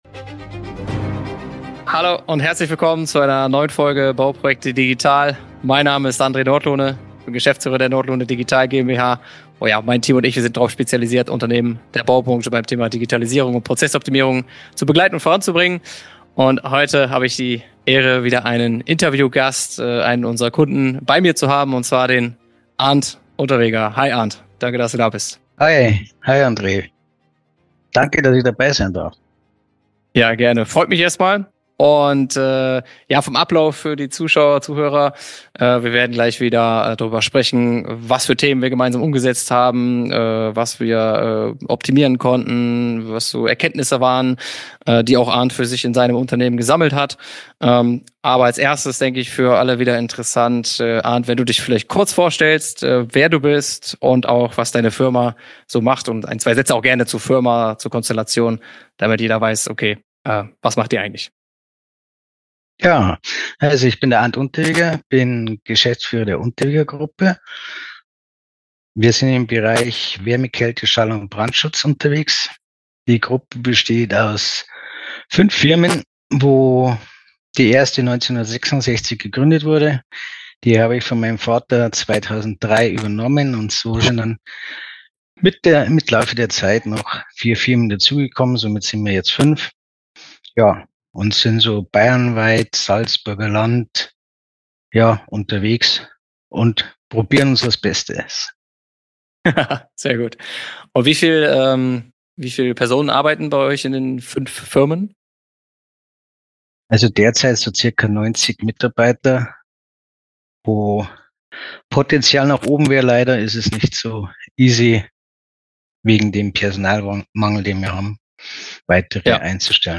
Papierloses Handwerksunternehmen - Interview